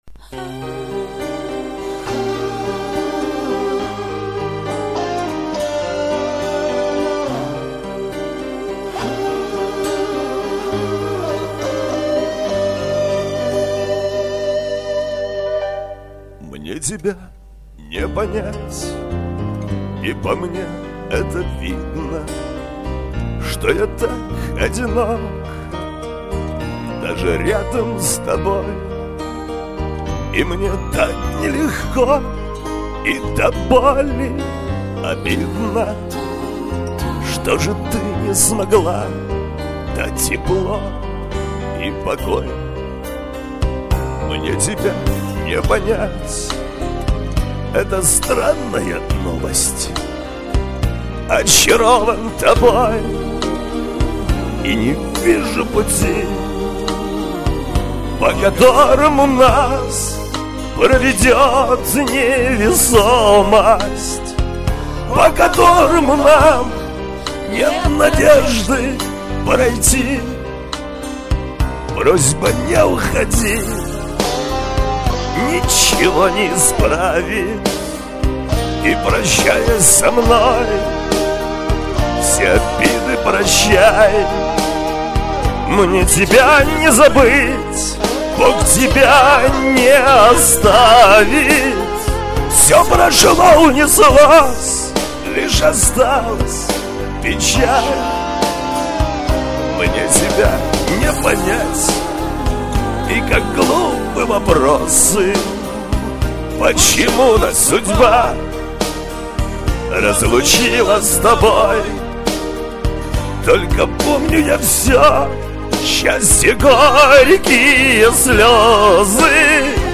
грустные песни выбрали...